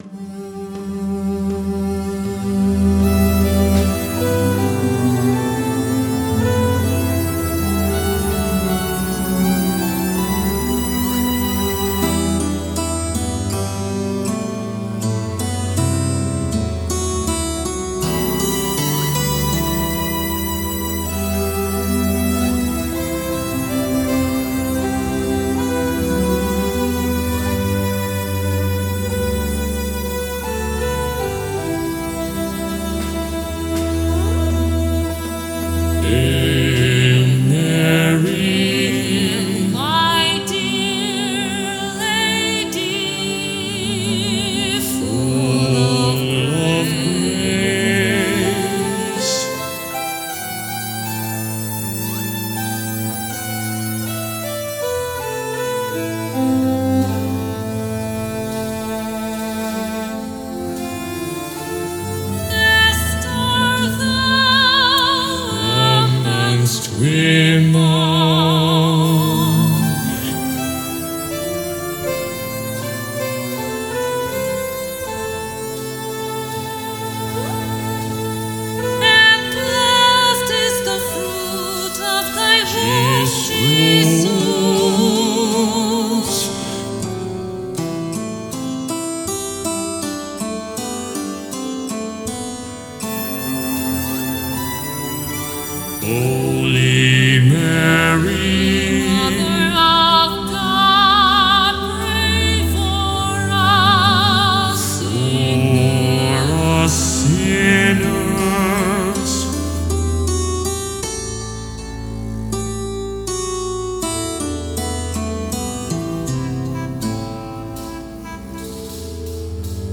música experimental